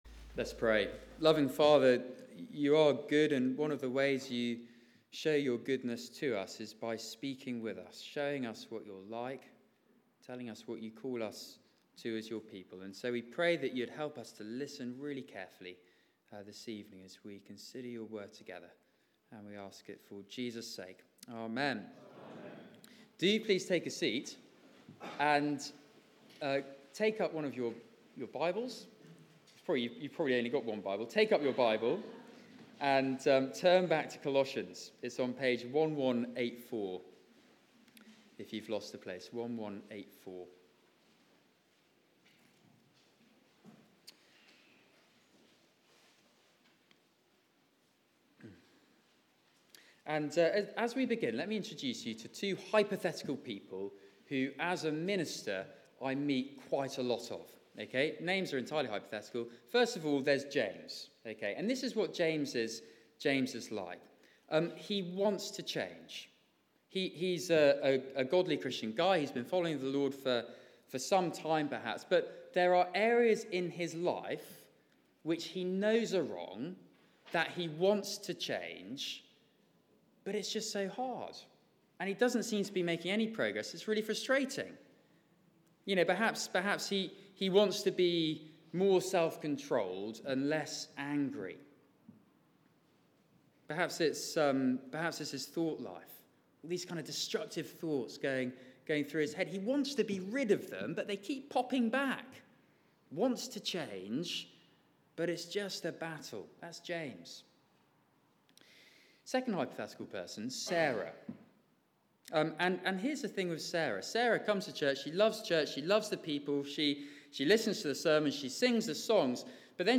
Media for 6:30pm Service on Sun 13th Nov 2016
Rooted in Christ Theme: Christ and the death of the old you Sermon